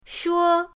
shuo1.mp3